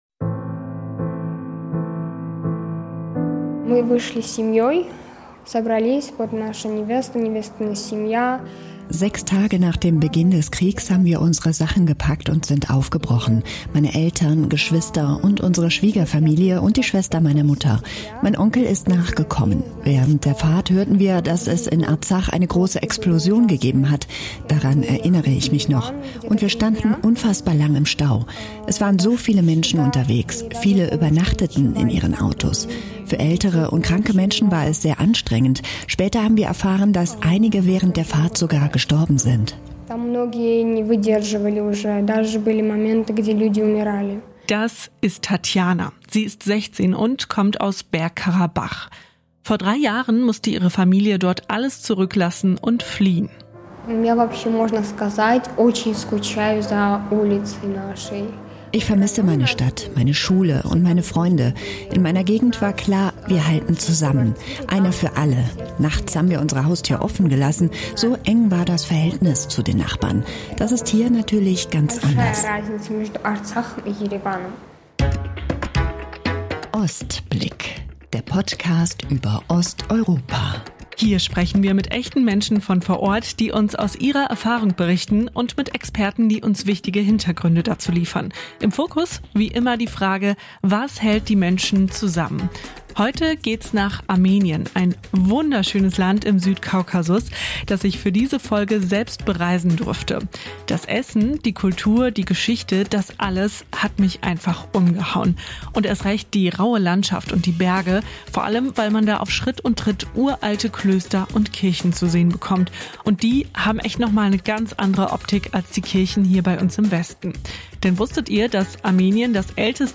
Wir sprechen mit einer jungen Geflüchteten über Angst, Verlust und den schwierigen Neuanfang. Und wir fragen: Wie gelingt Integration in einem Land, das selbst vor großen Herausforderungen steht?